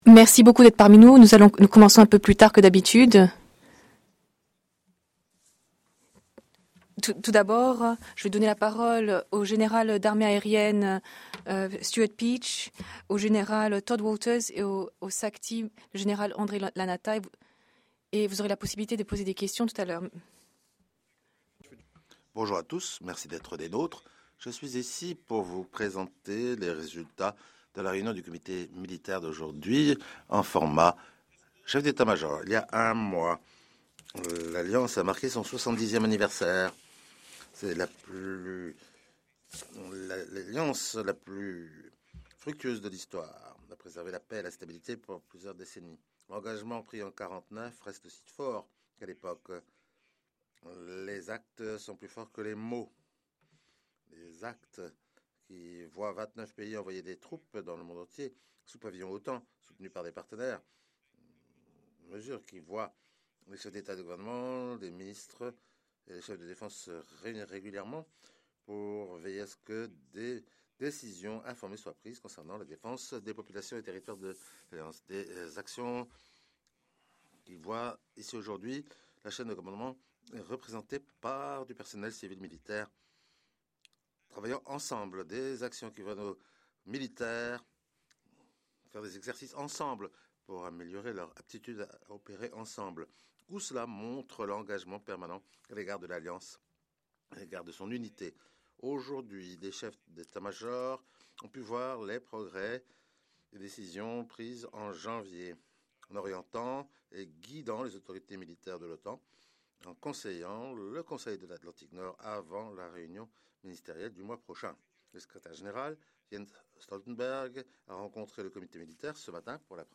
Opening remarks by Air Chief Marshal Sir Stuart Peach, Chairman of the NATO Military Committee